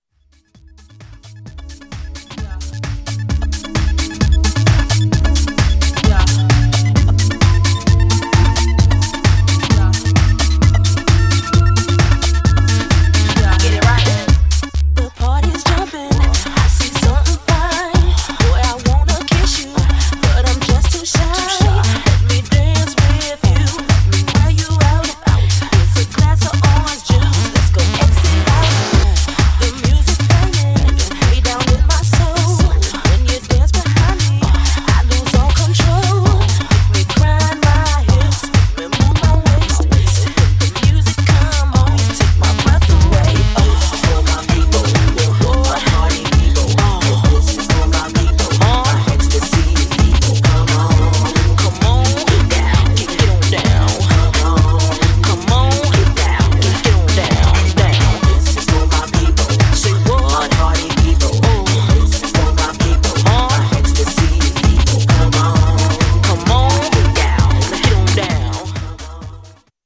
Styl: Hip Hop, House